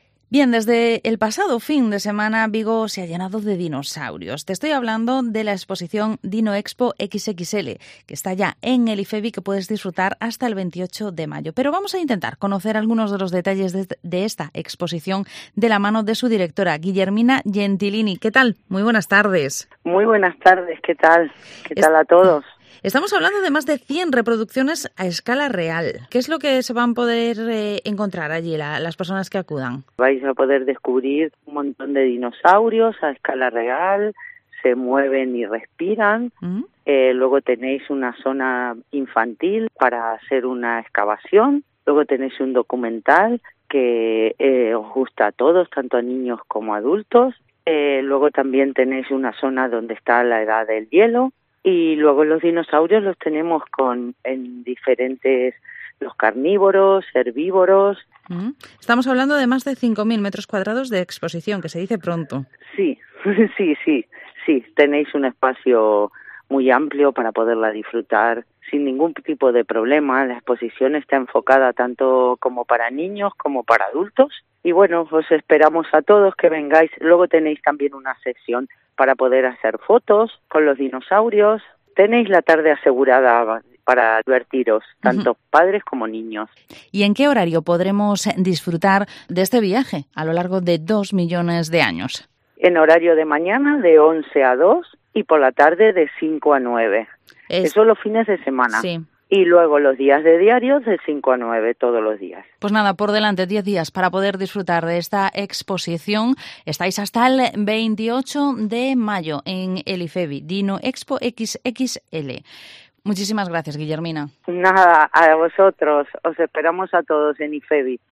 Vigo Entrevista ¿Te gustan los dinosaurios?